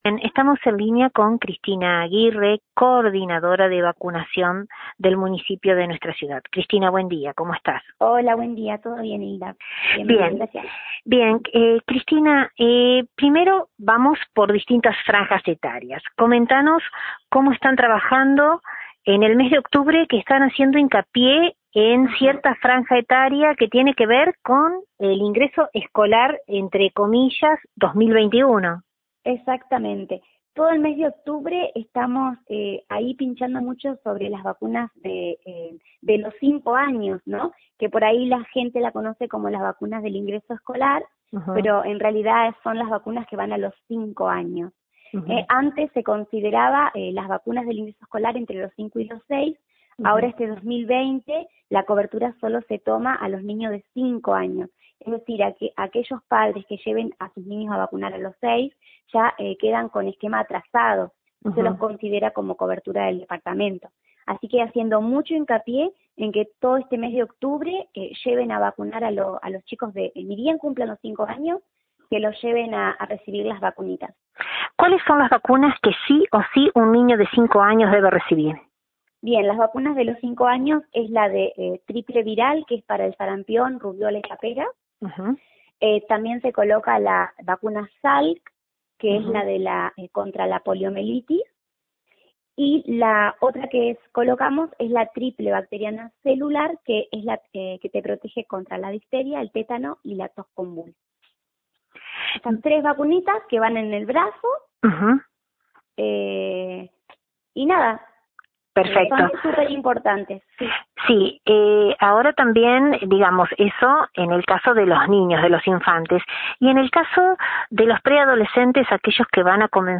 IMPORTANTE VACUNAS desde LT39 NOTICIAS dialogamos con la coordinadora del área local